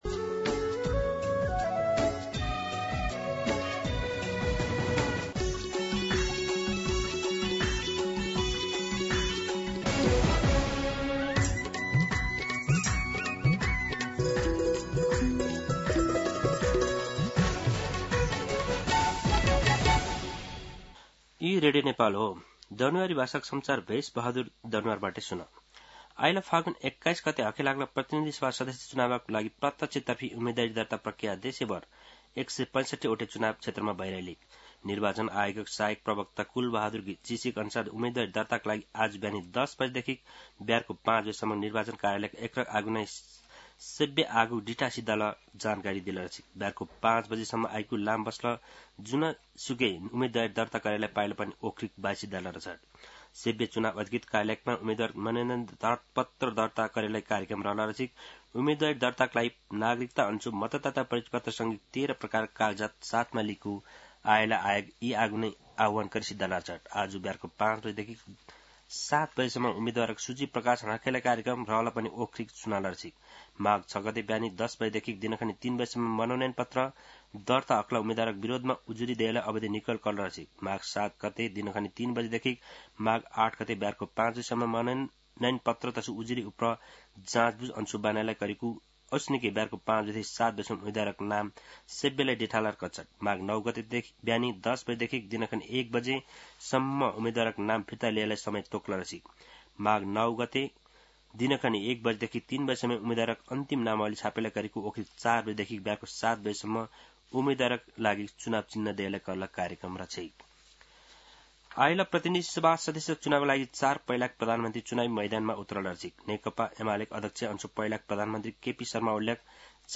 दनुवार भाषामा समाचार : ६ माघ , २०८२
Danuwar-News-06.mp3